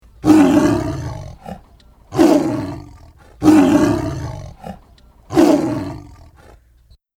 Zawiera 6 ścieżek dźwiękowych, za pomocą których dzieci poczują się, jakby były na safari lub w środku dżungli!
Na nas dorosłych usłyszenie nagranego ryku lwa, małpiego jazgotu czy trąbiącego słonia nie robi większego wrażenia.